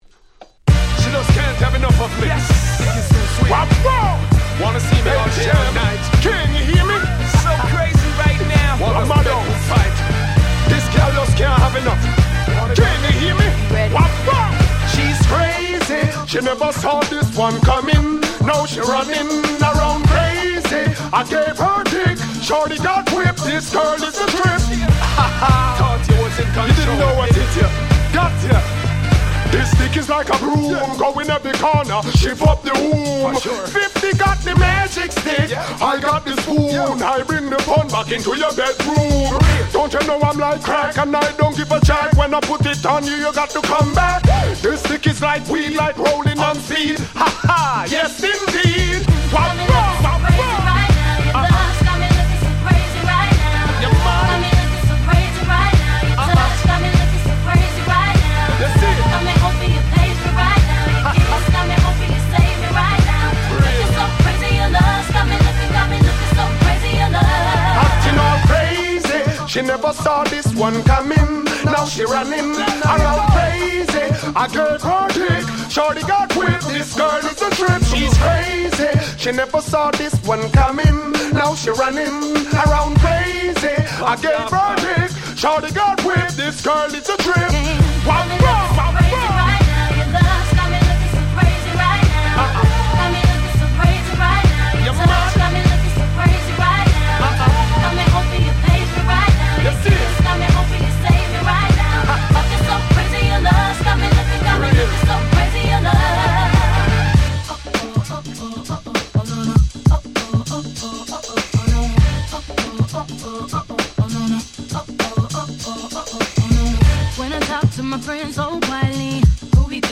03' Super Hit R&B !!
レゲエ
ダンスホール